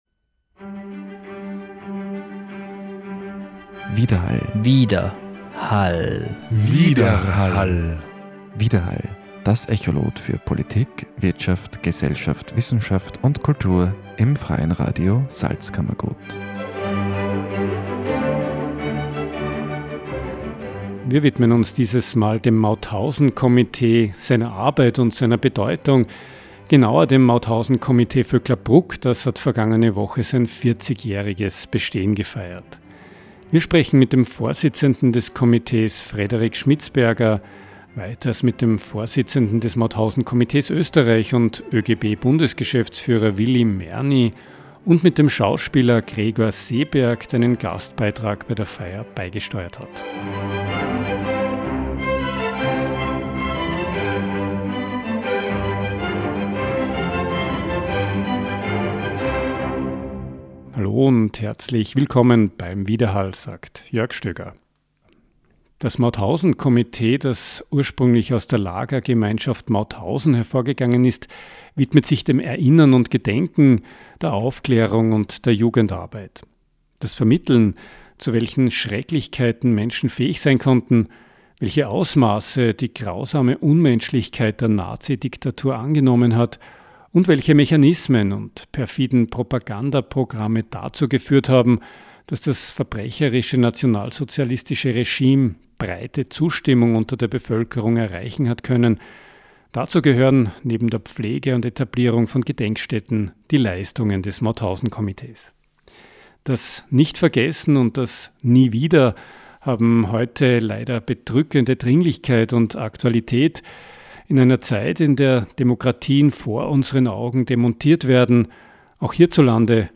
Gespräche